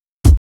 Kicks
WU_BD_070.wav